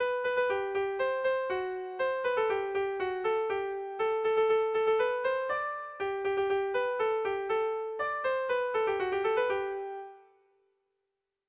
Dantzakoa
Arraioz < Baztan < Baztan Ibarra < Iruñeko Merindadea < Navarre < Basque Country